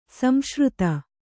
♪ samśruta